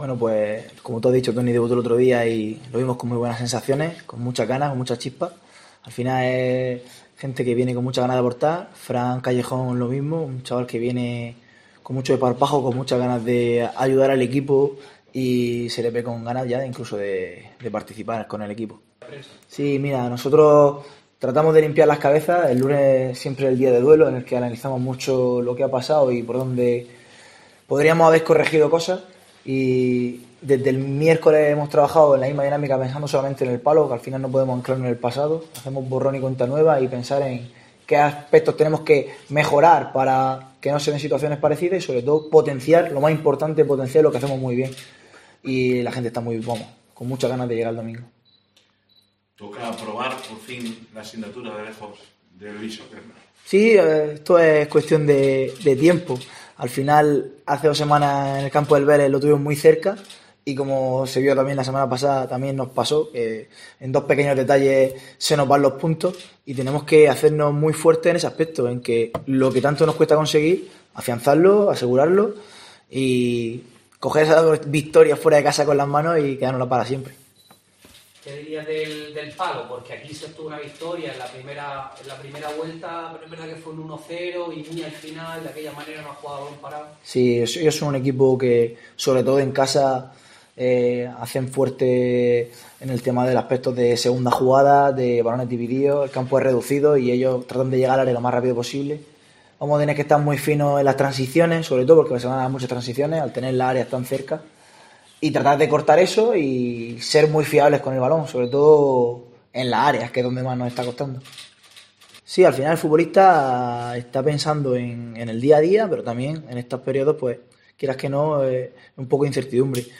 Esta rueda de prensa ha estado patrocinada por Natural D' Mezcla, empresa que se incorpora a nuestro UCAM Business Club.